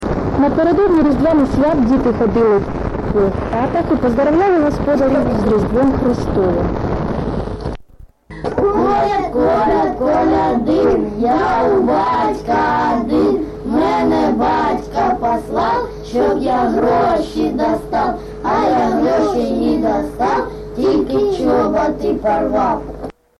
ЖанрКолядки